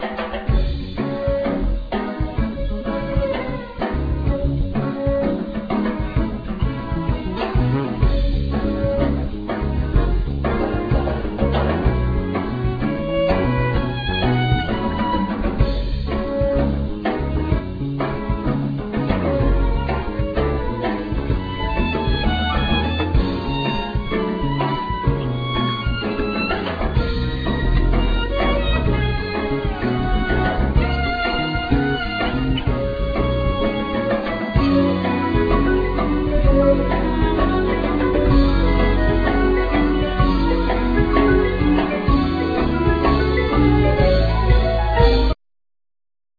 Accordeon,Vocals
Violin,Viola
Organ,Grand Piano,Pianet,Synthesizer,Vocals
Electric Basse,Bow,Darbuka,Guiro
Vibraphone,Marimba,Glockenspiel,Percussion
Drums,Bongos and Blocks